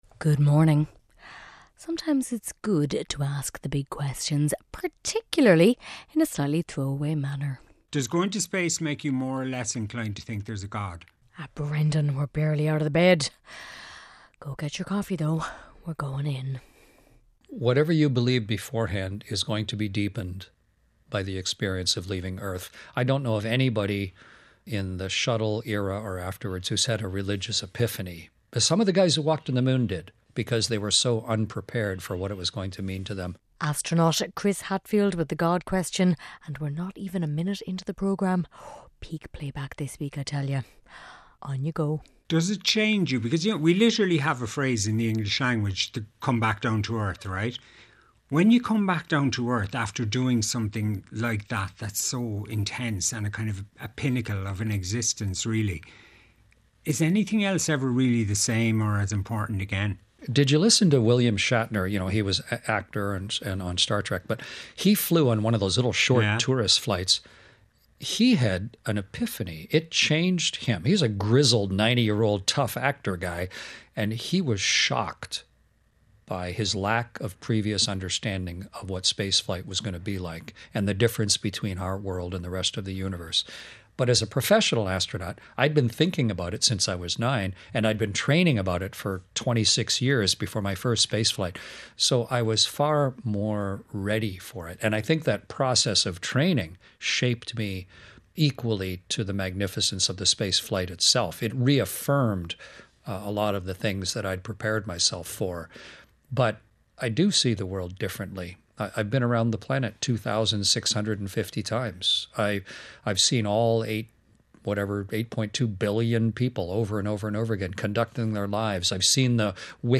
… continue reading 332 episodes # Ireland Current Affairs # Radio Programme # Ireland # RTÉ # International News # News # Society # RTÉ Radio 1